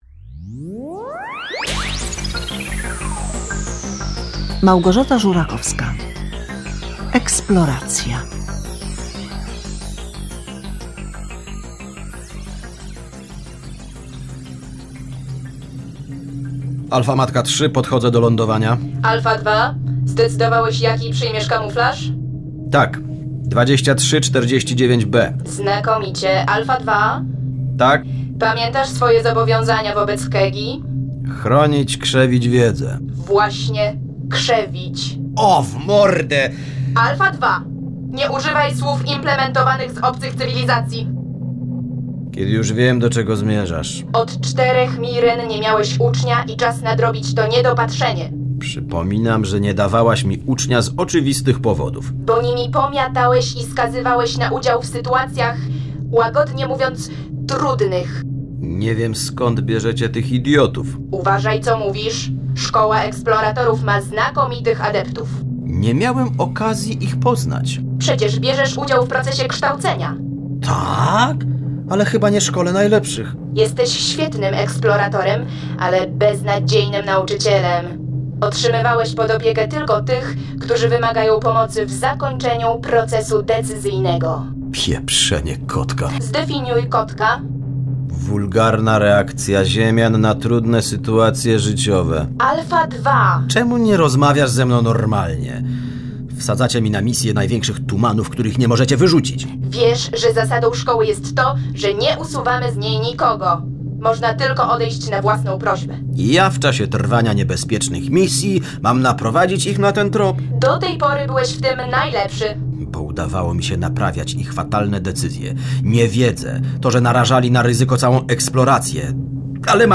W programie słuchowisko o pewnej wyprawie kosmicznej obcych cywilizacji, która chce poznać mieszkańców Ziemi.